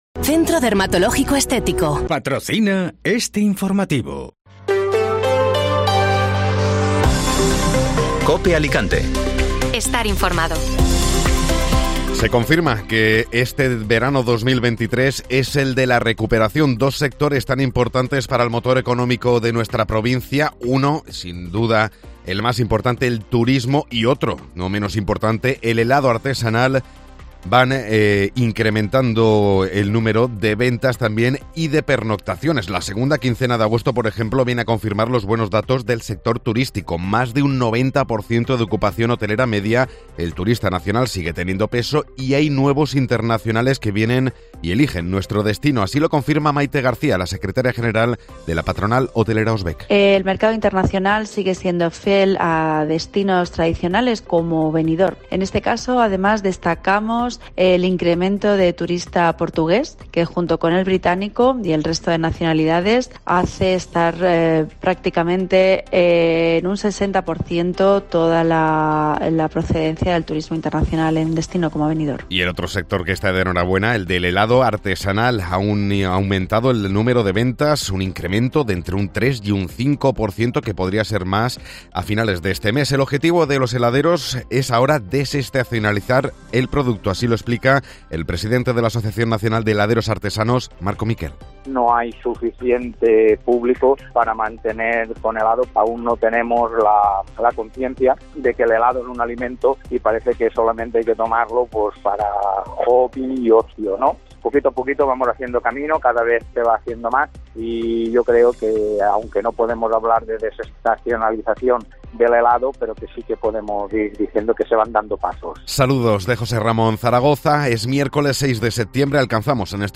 Informativo Matinal (Miércoles 6 de Septiembre)